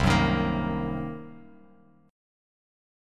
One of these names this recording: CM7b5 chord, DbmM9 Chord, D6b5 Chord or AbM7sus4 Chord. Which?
CM7b5 chord